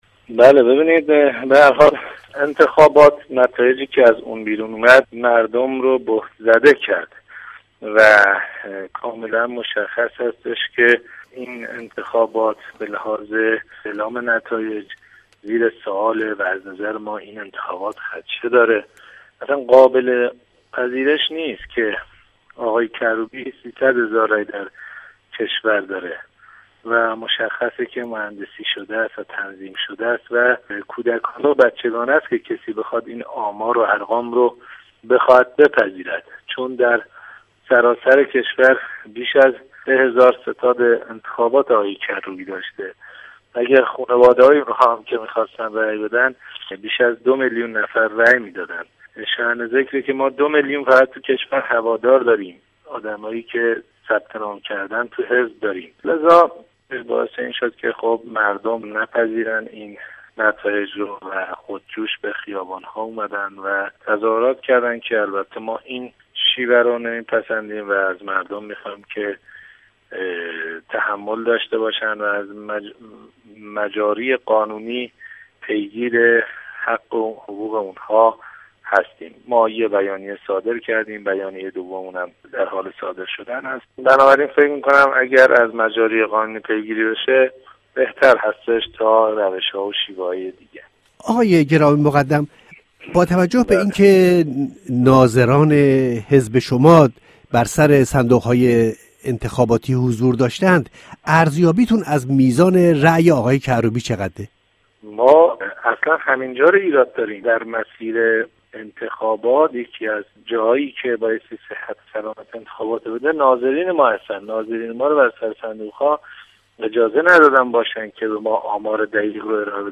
به همین دلیل برنامۀ این هفتۀ ما بدلیل شرایط ویژۀ این روزها مجموعه ای از مصاحبه های جداگانه با کسانی است که در این عرصه صاحب نظر بوده اند.